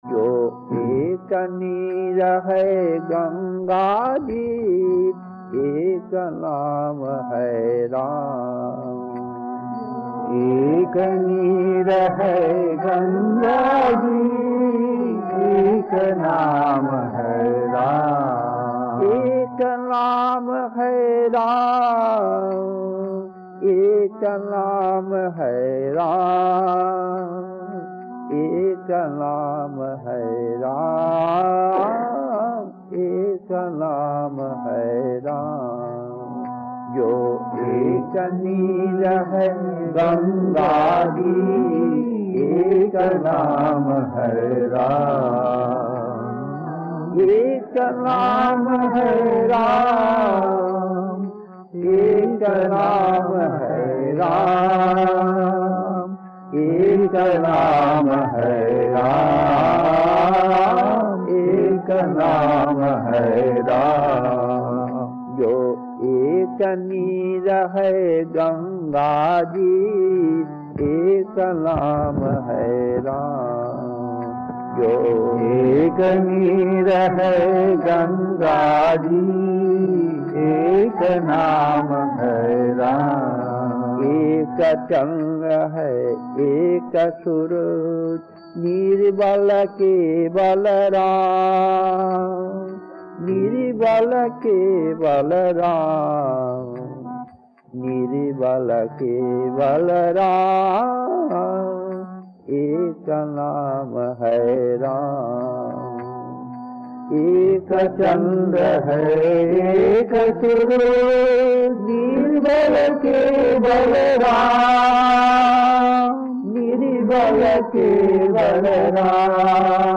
Kirtan C10-2 Rewa, December 1978, 56 minutes 1.